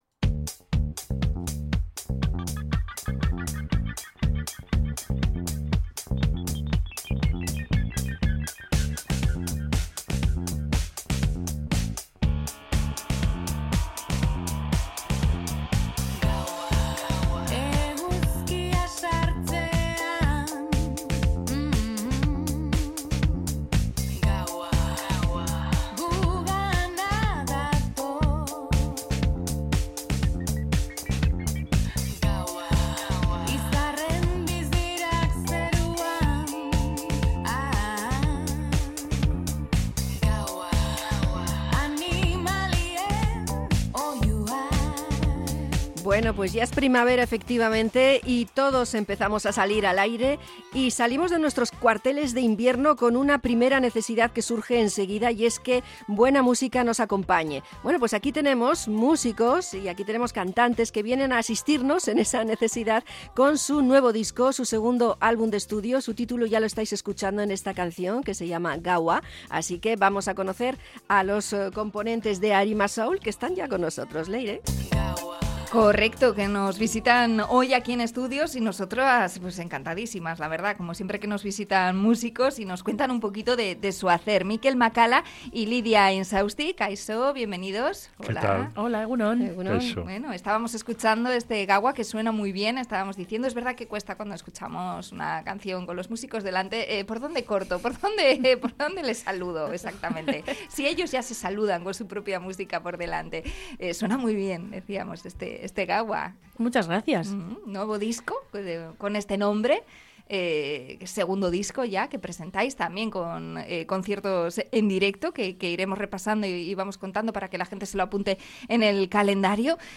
Entrevista al dúo musical Arima Soul